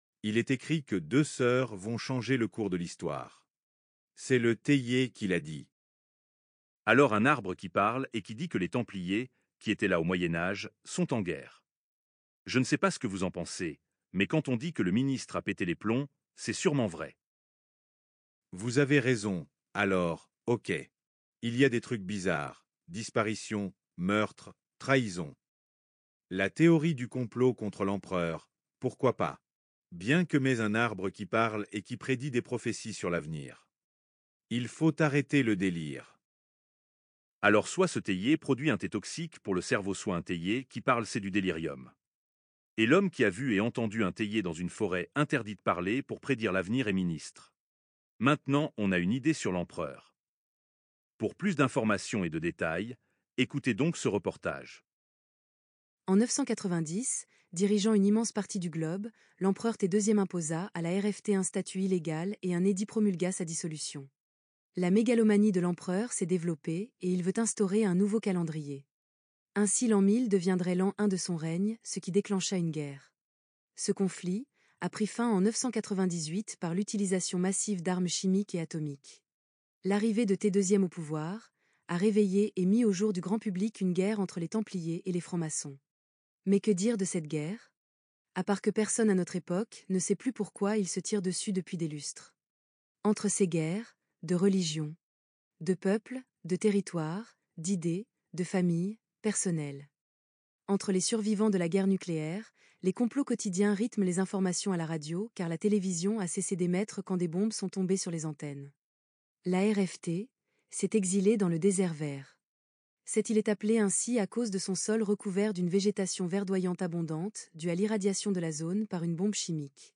reportage radio - II.wav